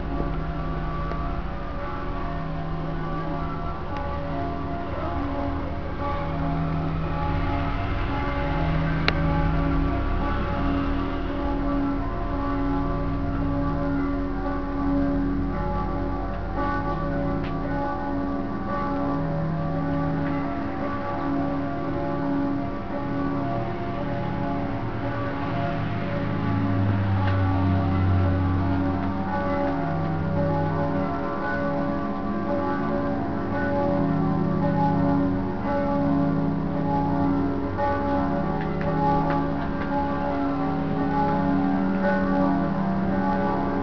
Click here to hear bells of Aug 15, 2000 - Brezje Basilica Marije Pomagaj